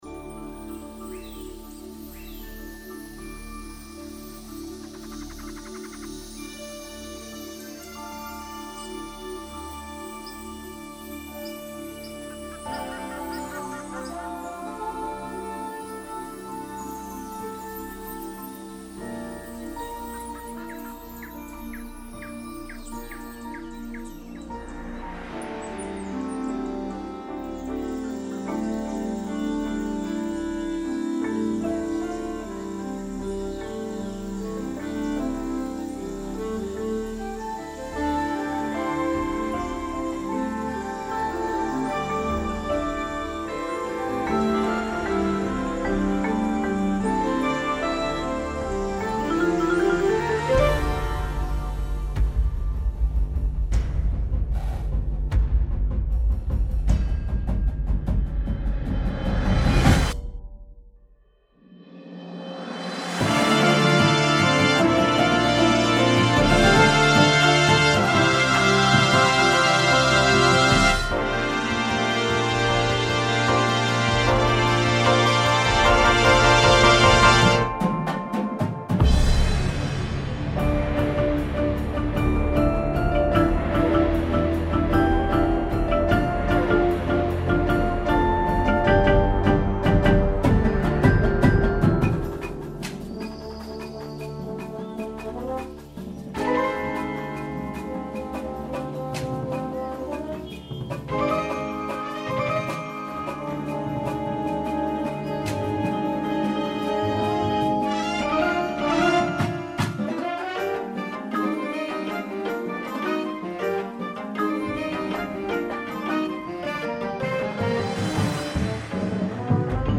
• Flute
• Bass Clarinet
• Tenor Sax
• Trombone
• Tuba
• Snare Drum
• Sound Effect Samples
• Marimba – Two parts
• Vibraphone – Two parts
• Glockenspiel